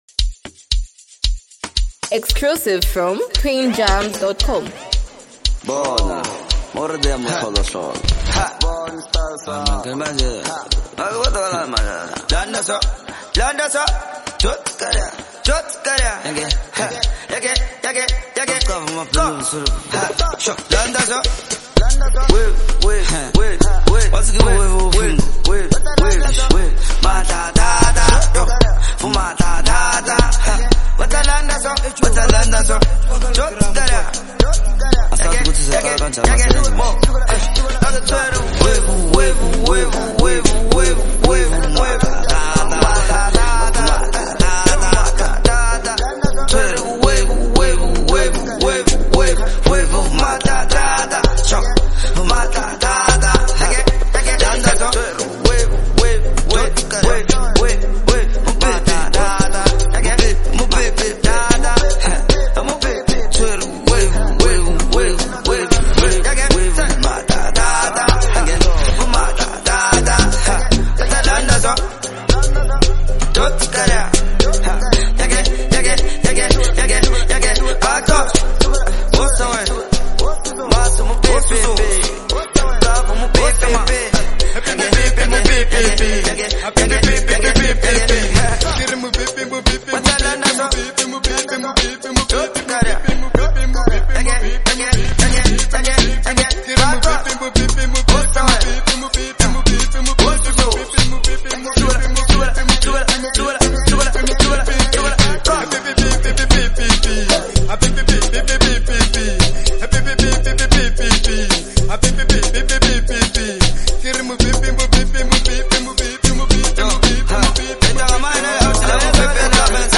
sets the pace with a solid hook, while
is a feel-good jam with strong replay value.